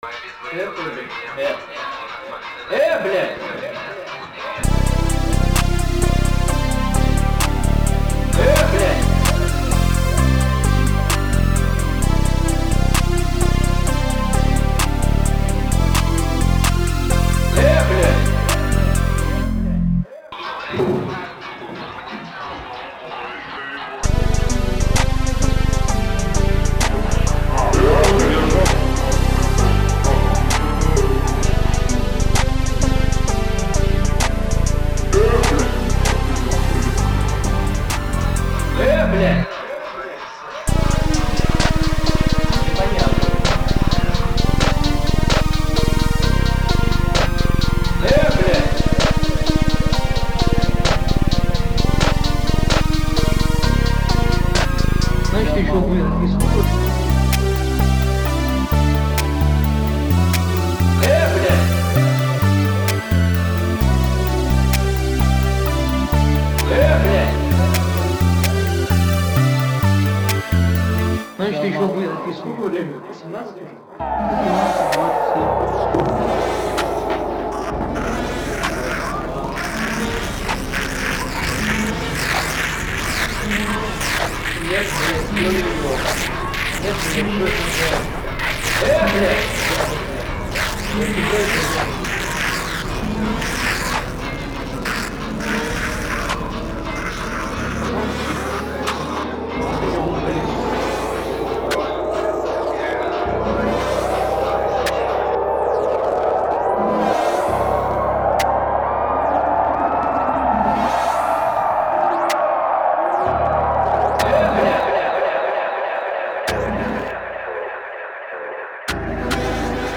Personally, I'm far from being involved in the craft of music production, but two of my friends make some pretty good beats.